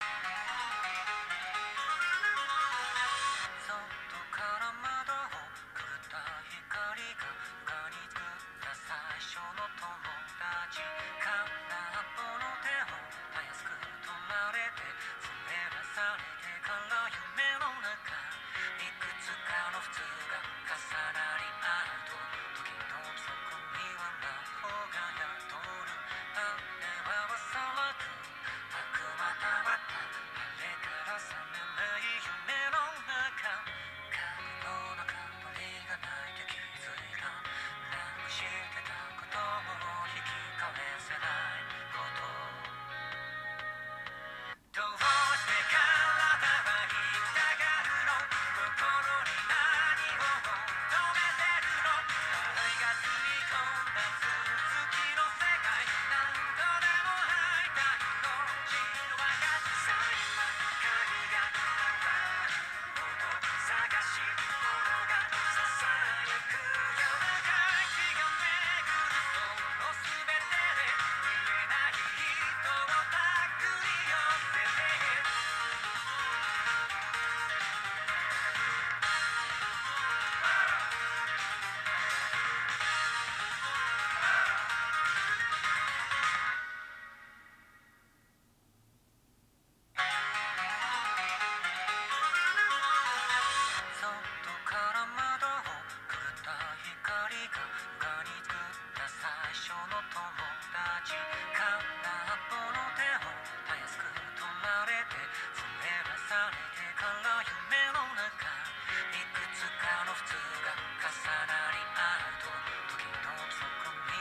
参考までに、ヘッドホンから聞こえるサウンドを録音したデータを掲載する。
▼ヘッドホンにマイクを近接させて録音。
※あくまで低音・高音の強さをなんとなく分かってもらうためのものなので要注意。実際には、よりクリアで響きの良いサウンドが耳に届けられる。